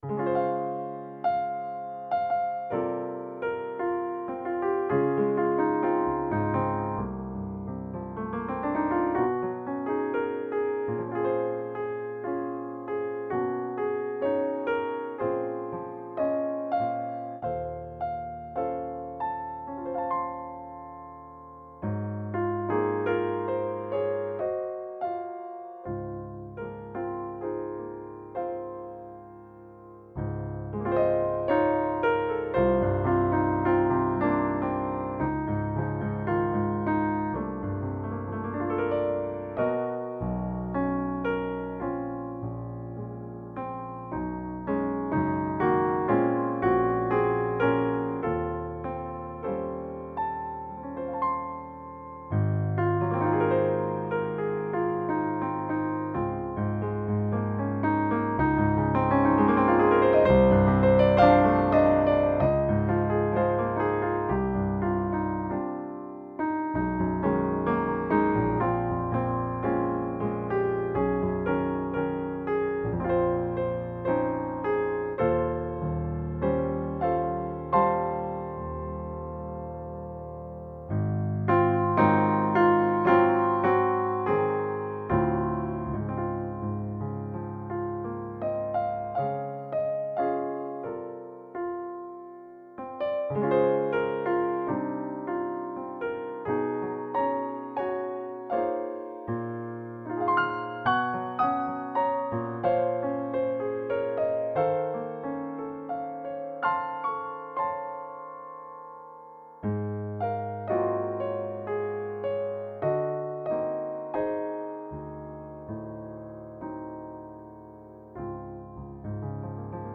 Klangproben